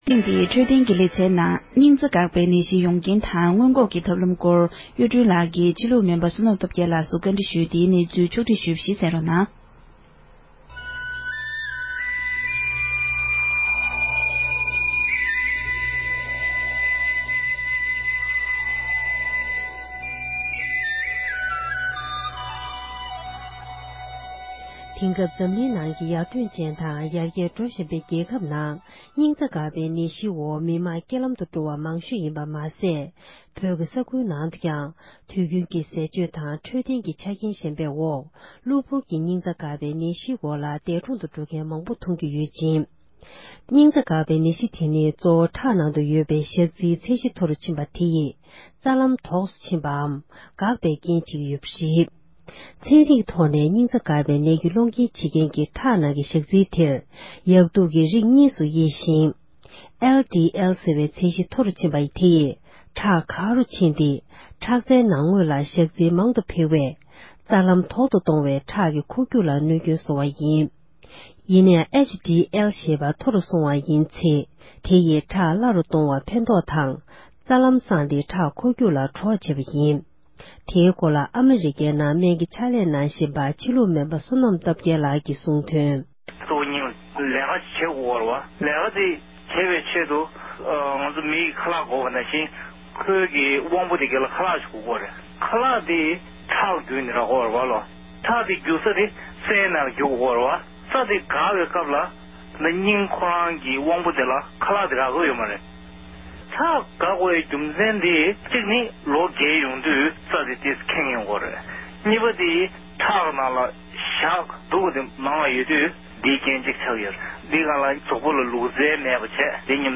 བཀའ་འདྲི་ཞུས་པར་གསན་རོགས་ཞུ༎